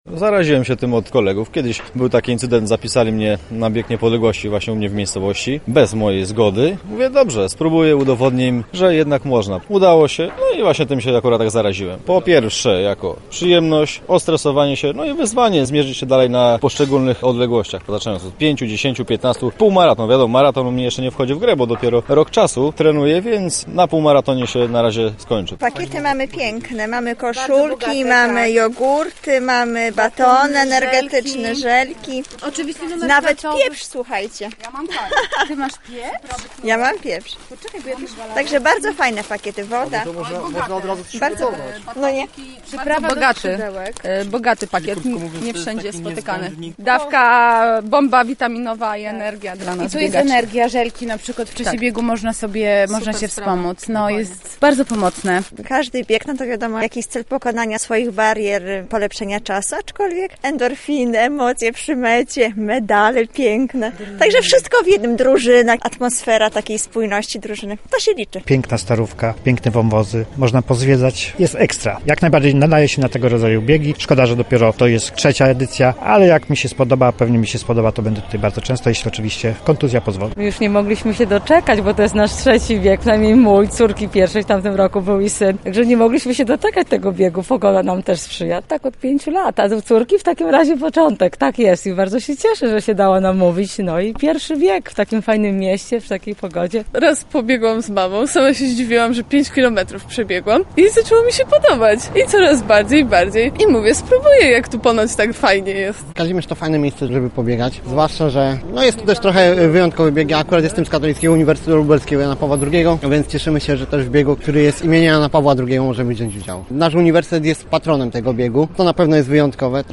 news-SONDA-Festiwal-Biegowy-im.-Jana-Pawła-II-w-Kazimierzu-Dolnym.mp3